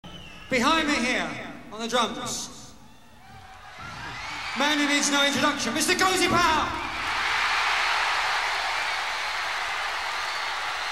「飛翔伝説」はライブアルバムです。
伝説の夜、武道館というシチュエーション、そして明らかに日本の聴衆とわかる「わー」という歓声が何とも言えぬ雰囲気を醸し出し、生コージー未体験の私のイマジネーションを刺激して止まないのであります。